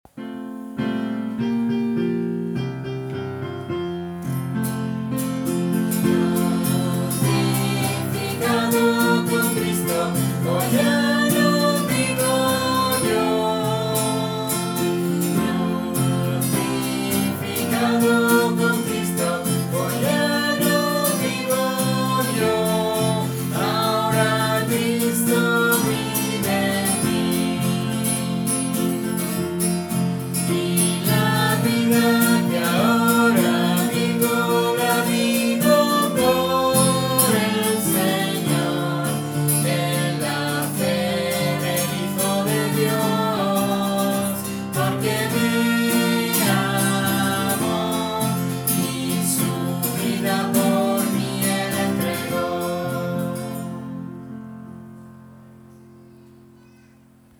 guitarra y piano